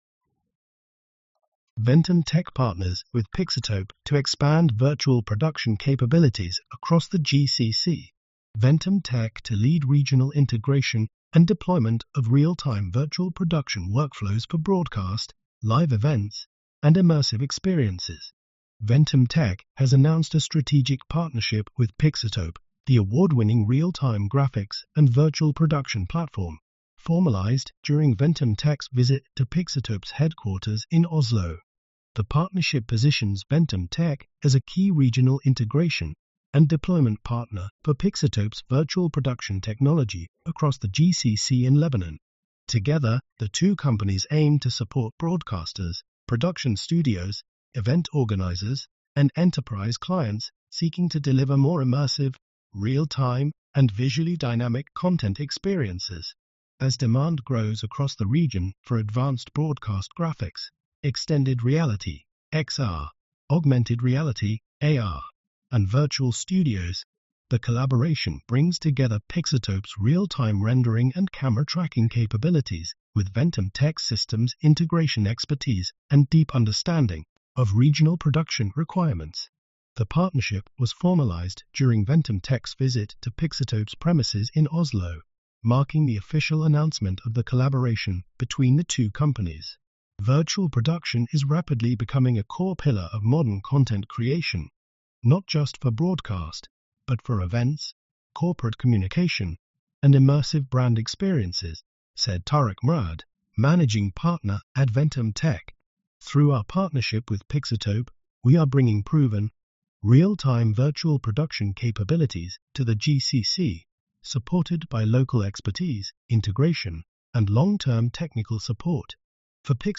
Audio description of article